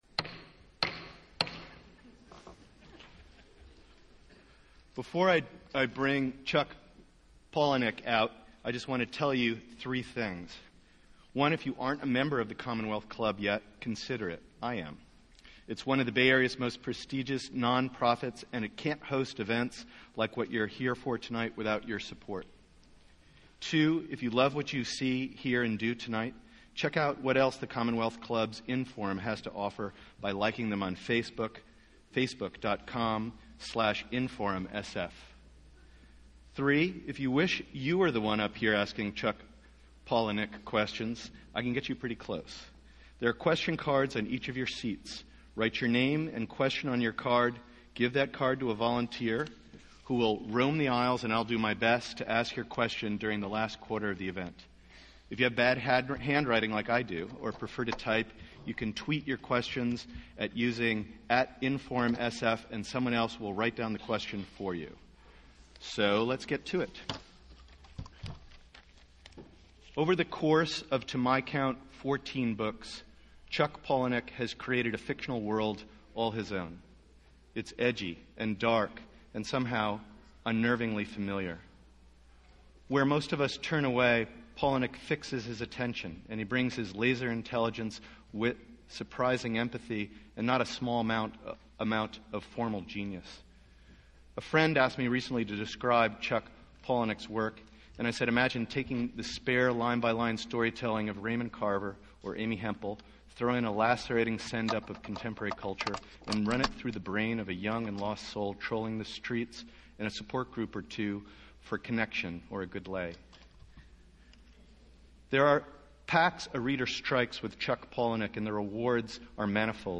Tickets will be available at the Castro Theatre at 3 p.m. Whoever said truth is stranger than fiction has never read Chuck Palahniuk.